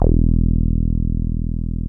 78.08 BASS.wav